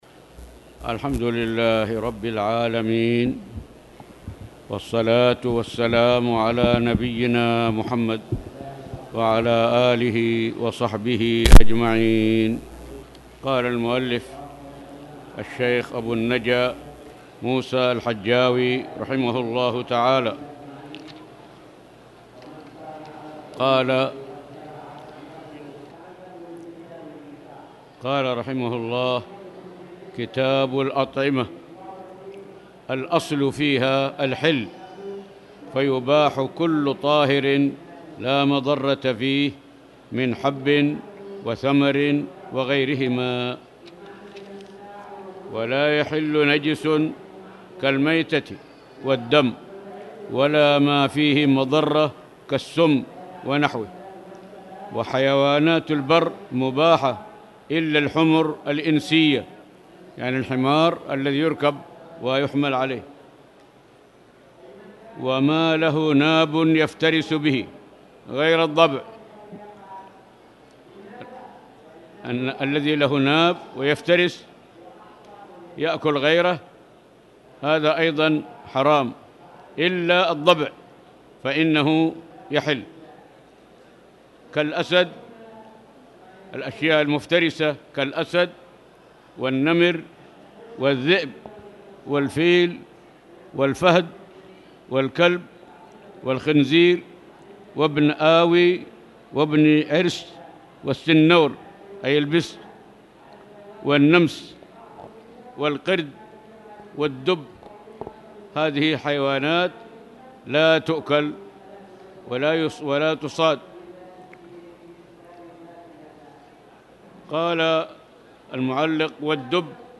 تاريخ النشر ٢٩ صفر ١٤٣٨ هـ المكان: المسجد الحرام الشيخ